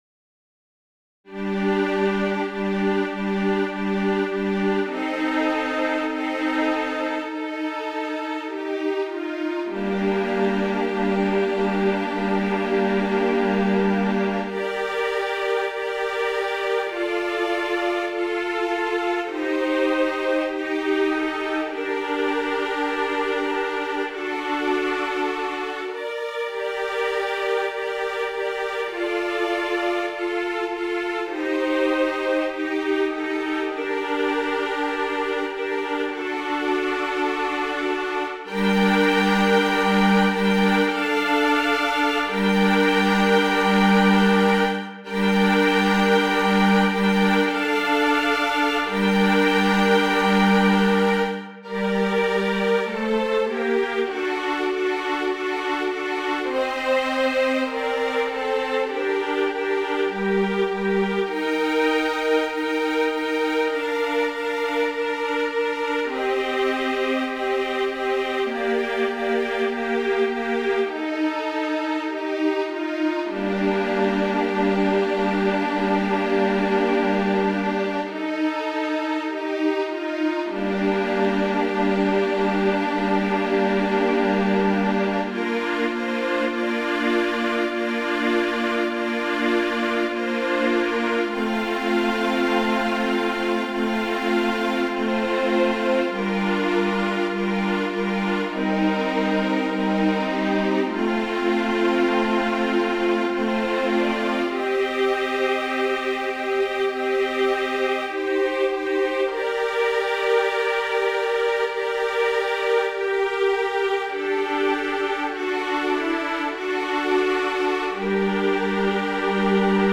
Number of voices: 4vv Voicing: SSAA Genre: Sacred, Unknown
Language: Latin Instruments: A cappella